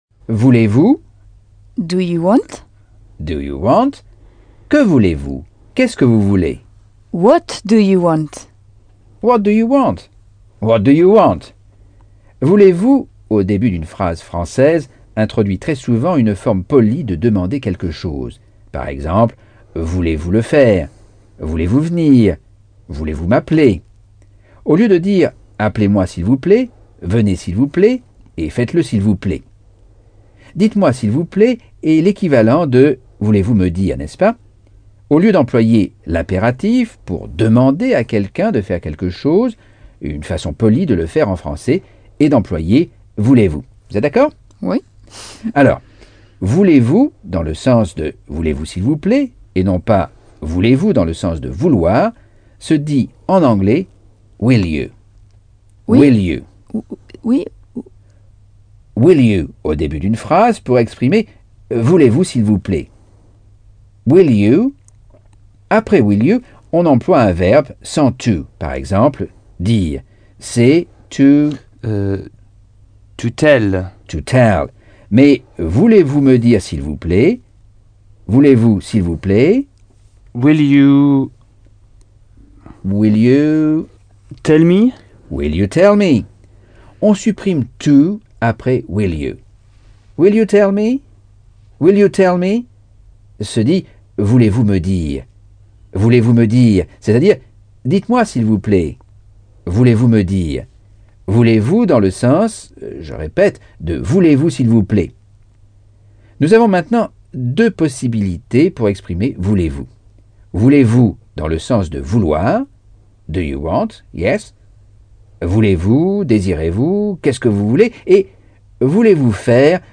Leçon 7 - Cours audio Anglais par Michel Thomas